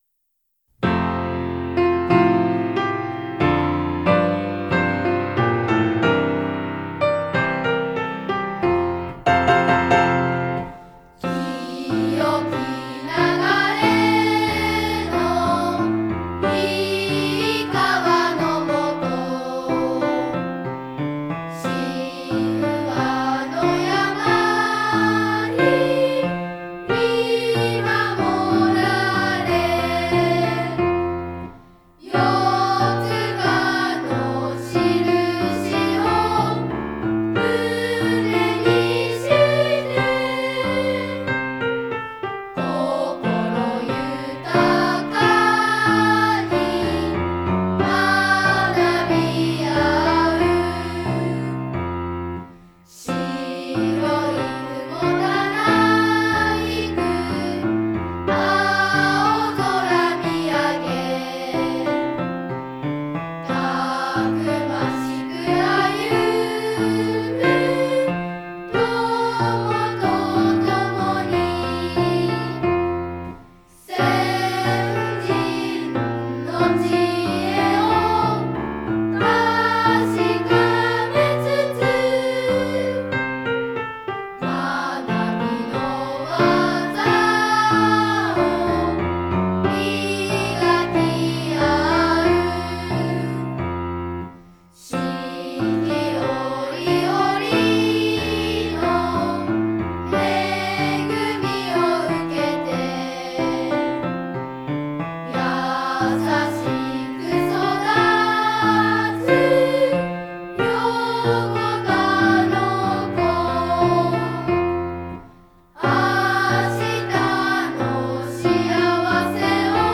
歌唱入り